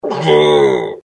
BELCH